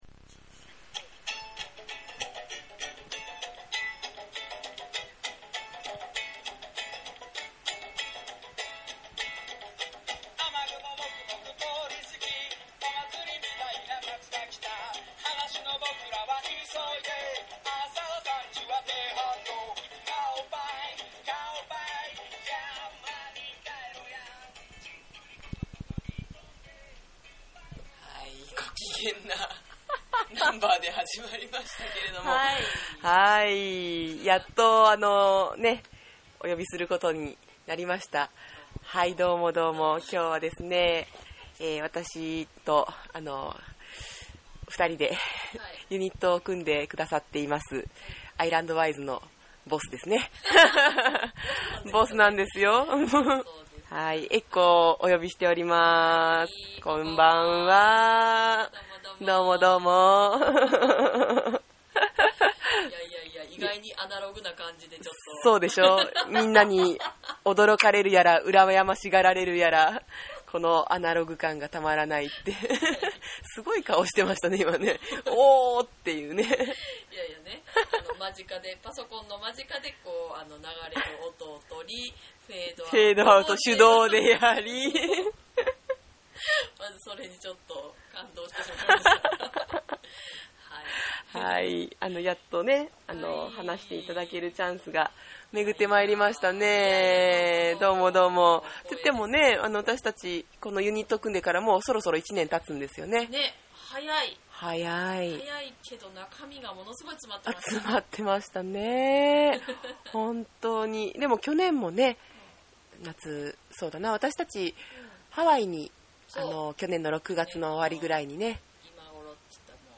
音で世界をつなぐ旅を続ける２人の、初！旅種♪トークをお楽しみ下さい☆